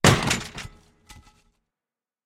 ladder-break.ogg.mp3